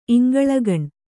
♪ iŋgaḷagaṇ